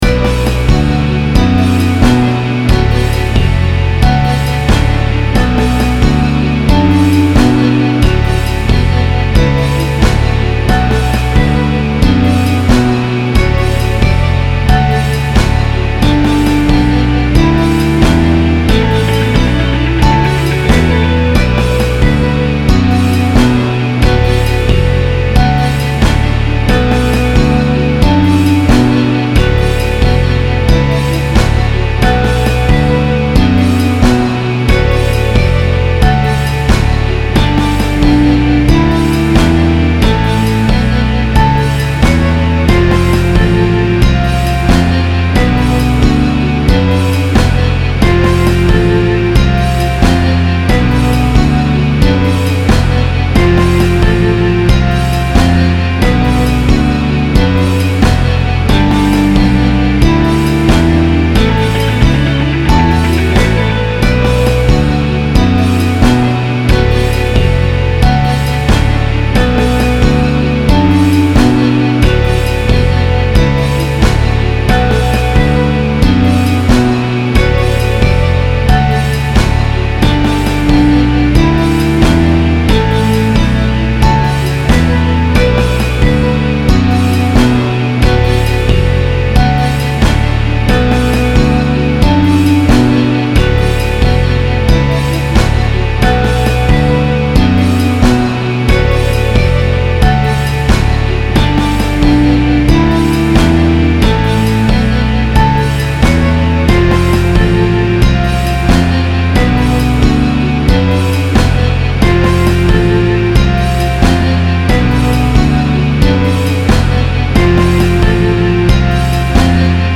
This is a headphone mix, which is the way I mix when I am working on a song . . . ]
-- Basic Rhythm Section, MachFive 3 Vibrato Wurlitzer and Jazz Stratocaster with Scripted Tremolo, and Xpansion Tank 2 Crunch Stratocaster -- MP3
I-Want-To-Dance-With-You-MF3-Wurlitzer-Jazz-Strat-ST-Crunch-Strat.mp3